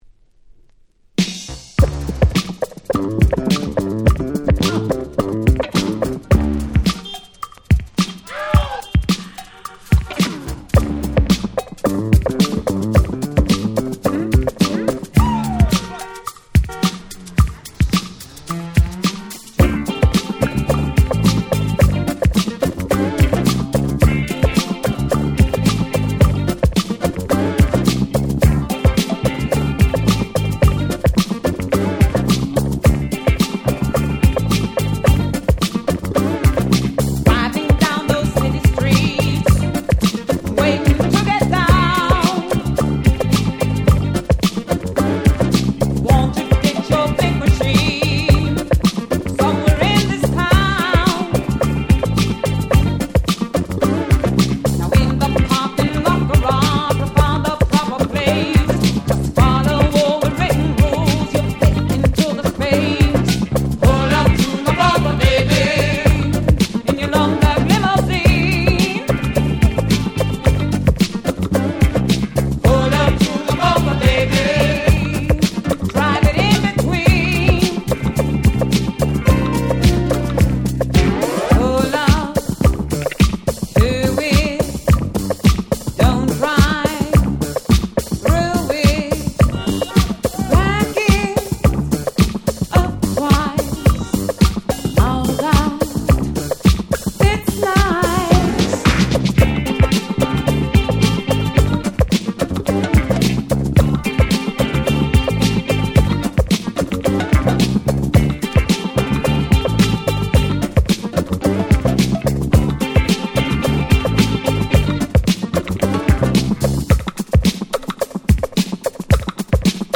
81' Super Hit Disco !!
ポコポコしたBeatが非常にクセになります！！
Dance Classics Disco